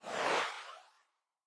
macOSsystemsounds
mail-sent.mp3